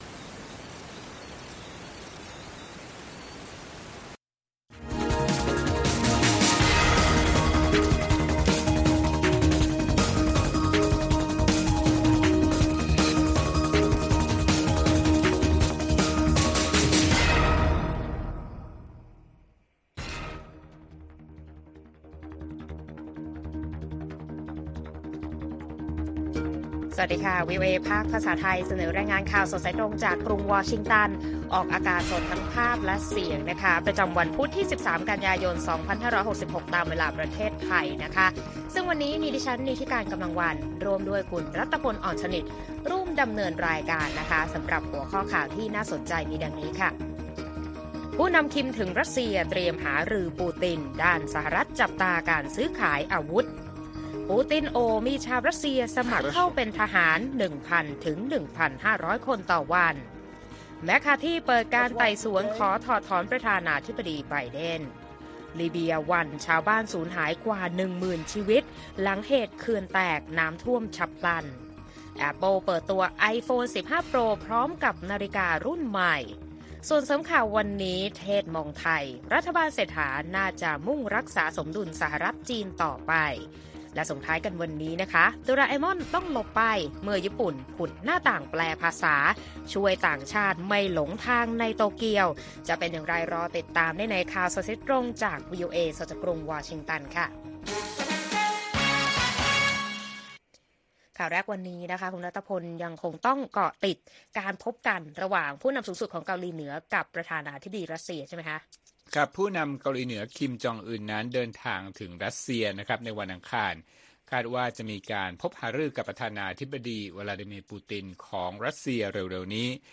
ข่าวสดสายตรงจากวีโอเอ ไทย พุธ 13 กันยายน 2566